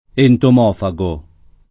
vai all'elenco alfabetico delle voci ingrandisci il carattere 100% rimpicciolisci il carattere stampa invia tramite posta elettronica codividi su Facebook entomofago [ entom 0 fa g o ] agg. e s. m. (zool.); pl. m. -gi